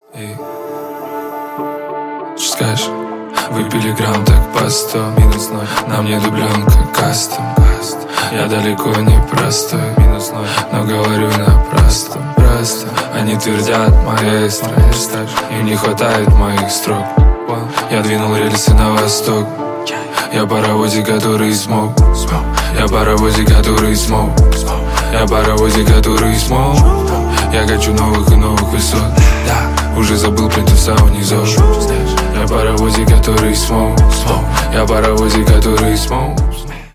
рэп